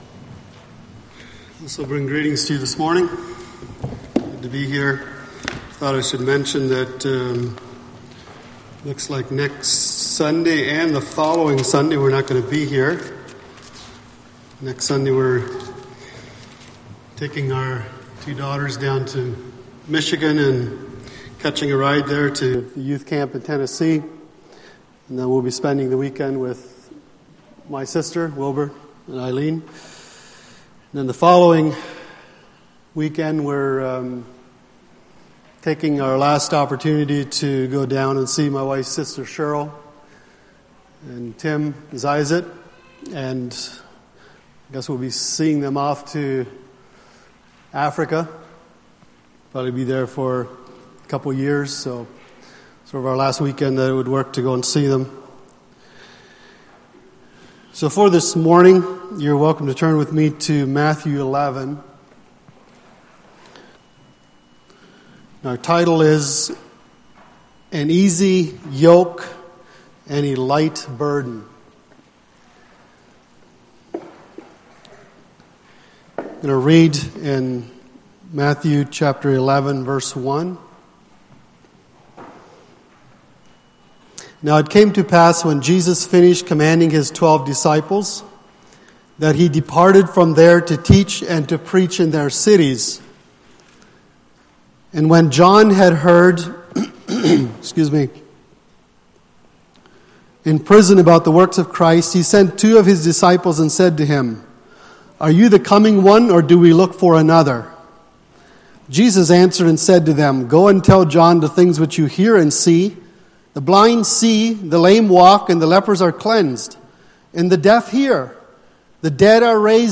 Sunday Morning Sermon Service Type